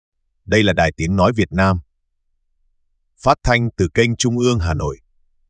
F5-TTS Vietnamese Text-to-Speech
It excels in zero-shot voice cloning and produces natural Vietnamese speech.
A Vietnamese text-to-speech model that converts input text into speech using reference audio for voice cloning with adjustable speech parameters.
"speed": 1,